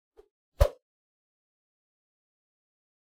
meleeattack-swoosh-light-group01-00.ogg